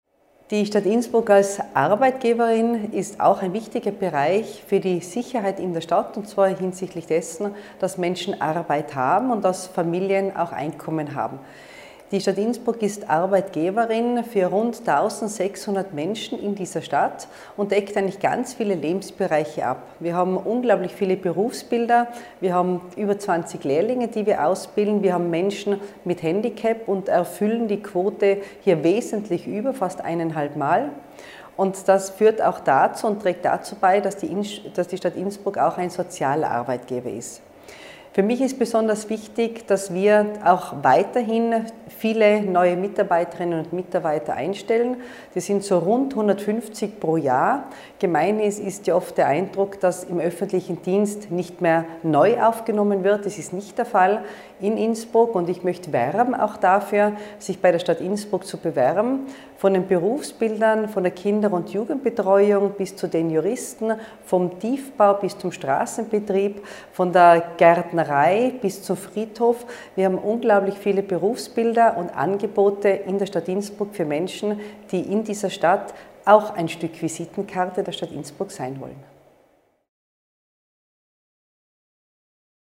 OT von Bürgermeisterin Christine Oppitz-Plörer